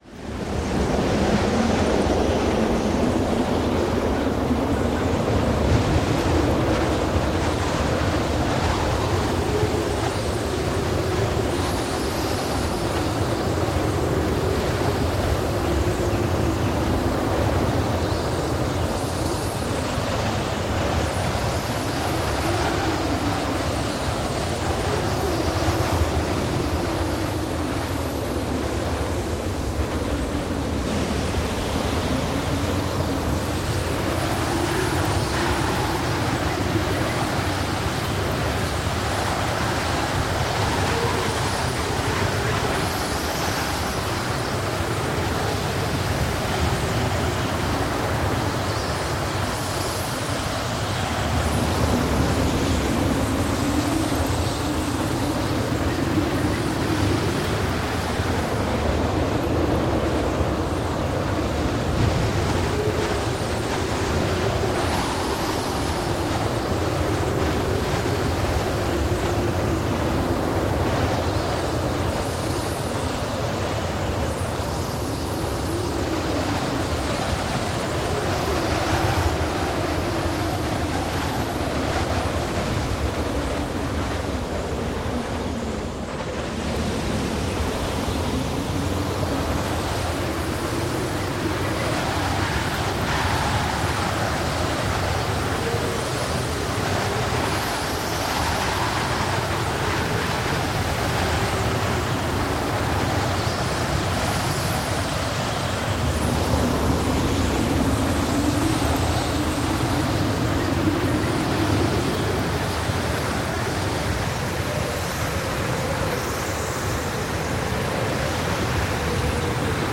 Звуки Антарктиды
Звук атмосферы ледяного ветра в Арктике